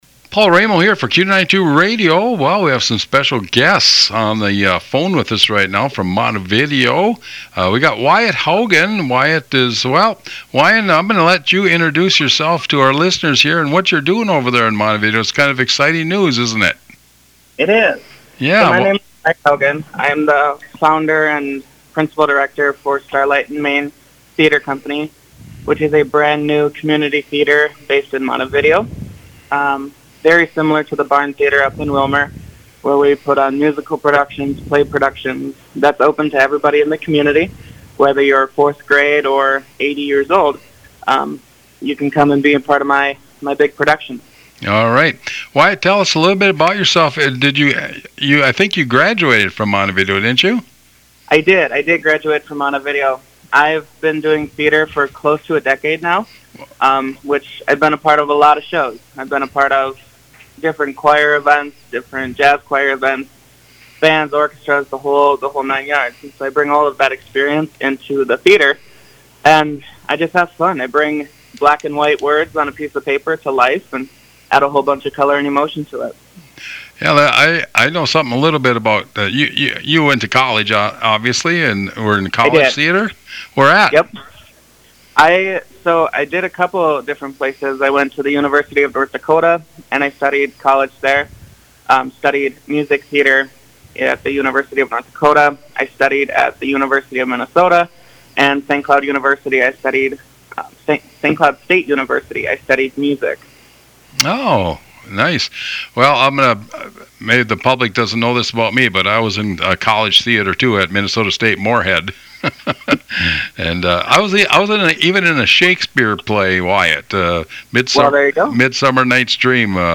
MONTEVIDEO COMMUNITY THEATER INTERVIEW